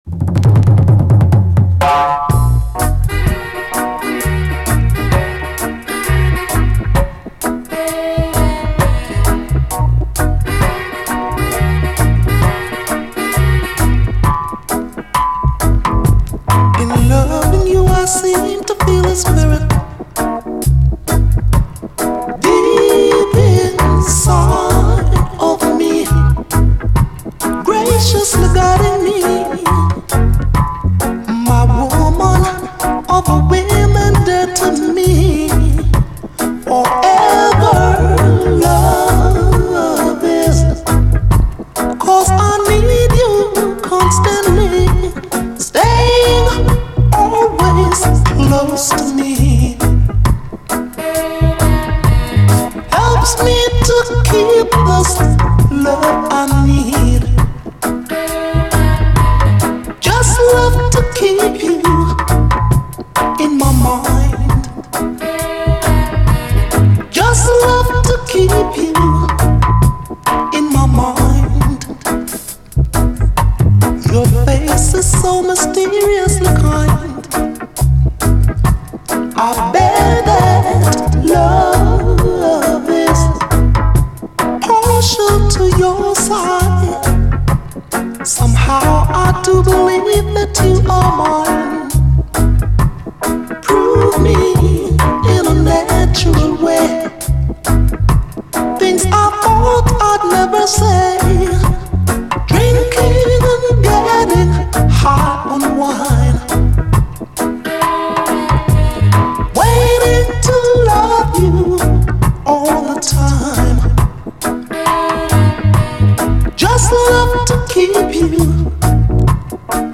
REGGAE, 7INCH
グレイト・スウィート・レゲエ！
トロリと柔らかい質感のメロウ・ソウル的グレイト・スウィート・レゲエ！